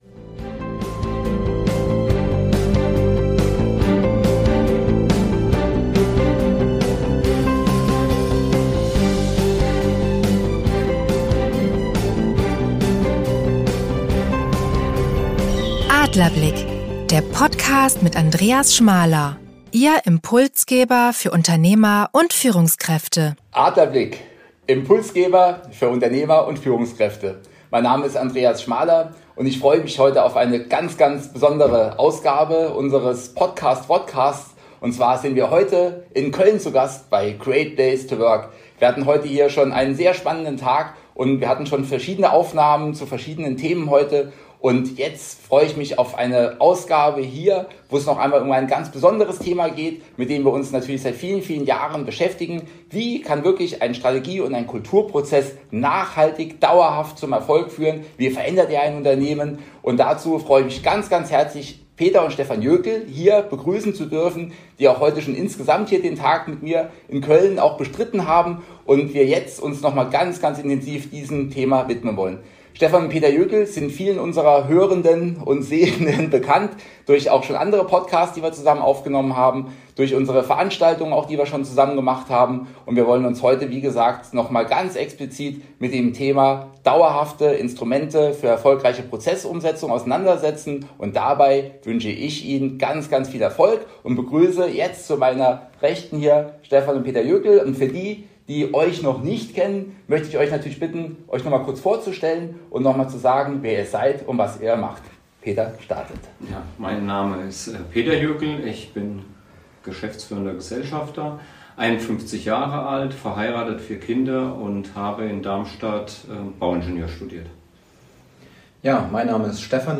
Beschreibung vor 2 Jahren Am gestrigen Tag wurde ein besonderer Podcast direkt in der Great Place to Work Zentrale in Köln aufgenommen.